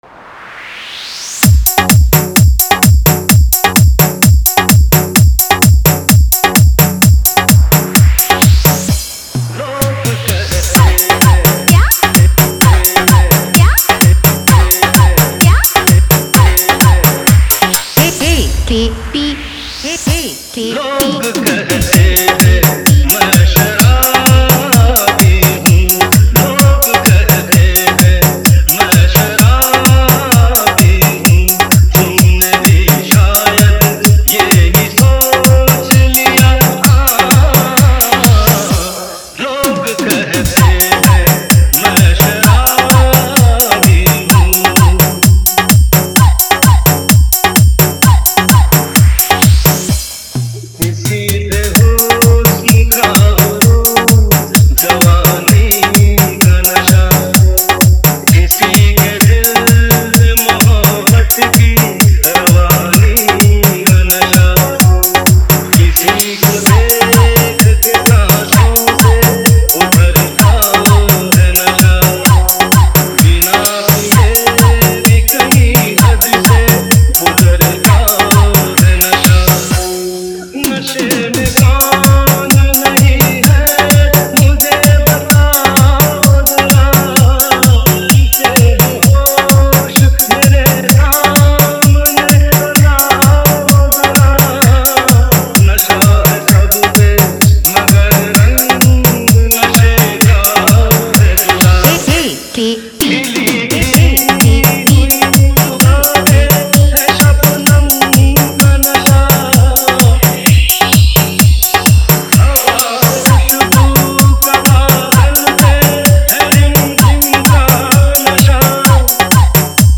EDM Remix
Dholki Remix
Category: Holi Dj Songs 2022